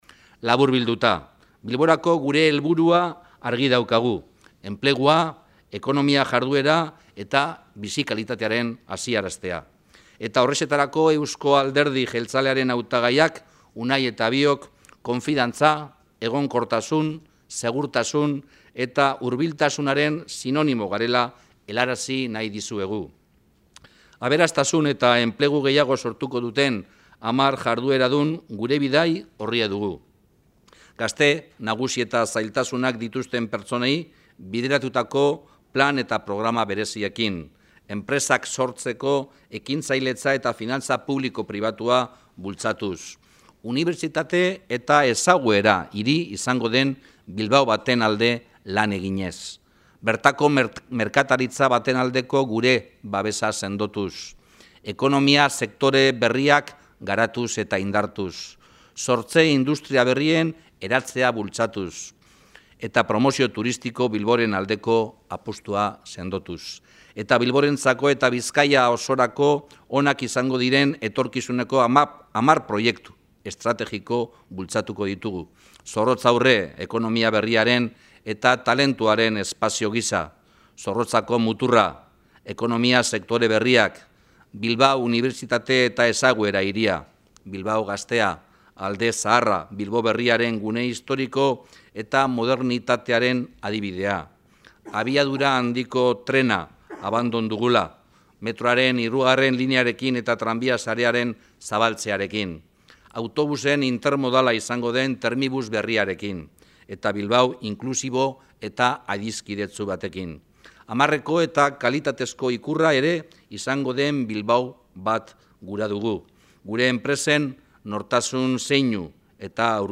Unai Rementeria, Bizkaiko ahaldun nagusirako EAJ-PNVren hautagia, eta Juan Mari Aburto, Bilboko Alkatetzarako hautagai jeltzalea, Bilboko Meliá hotelean elkartu dira gaur goizean, herrialdeko eta hiriburuko enpresa eta ekonomia sektoreko hogei bat eragile eta ordezkarien aurrean ekonomia suspertzeko eta enplegua sortzen laguntzeko planteatuko dituzten proposamenak aurkezteko.